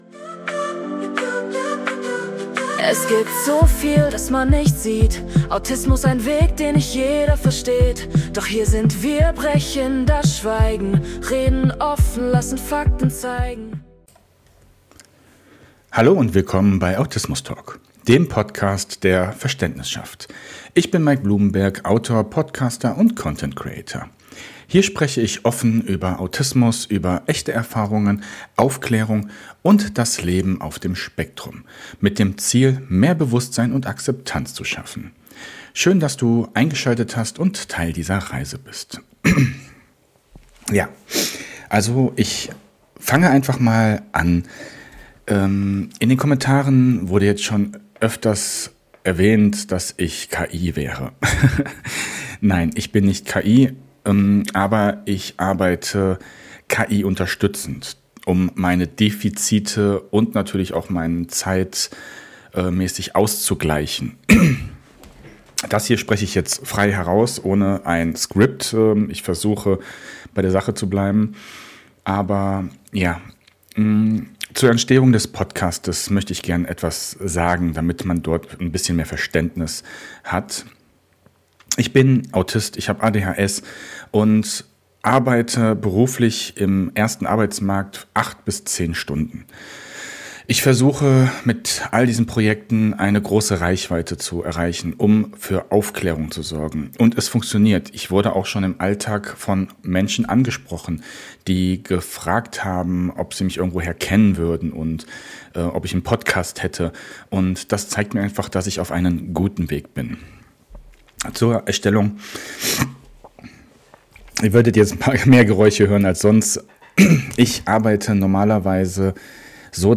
ADHS bei Kindern (Ohne Bearbeitung) ~ Autismus Talk Podcast